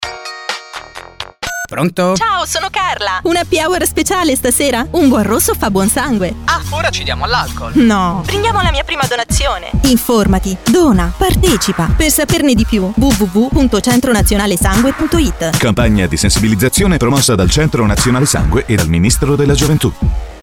Condividi Data : 23/07/2010 Categoria: Altre News Si rivolge ai giovani la campagna di sensibilizzazione proposta da Centro nazionale sangue e Governo. Lo spot in onda ad agosto sulle radio più “hot” sulle coste italiane Happy Hour per tutti?